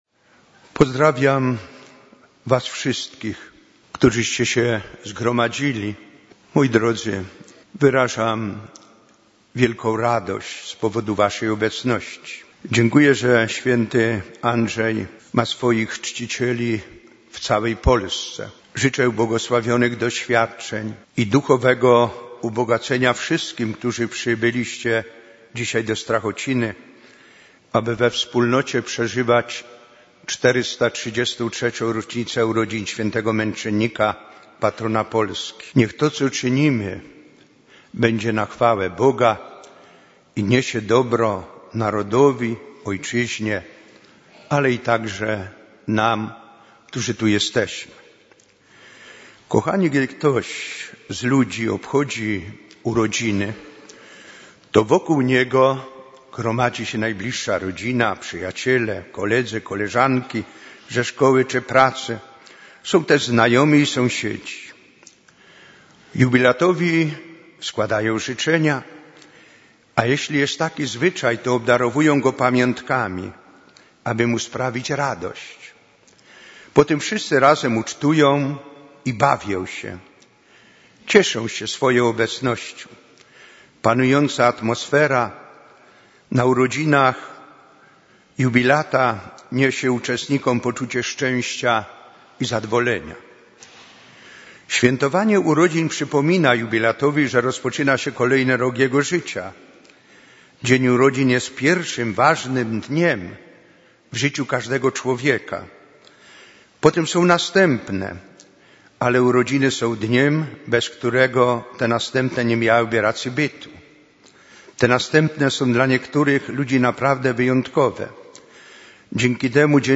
KAZANIA DO SŁUCHANIA - KU CZCI ŚW. ANDRZEJA BOBOLI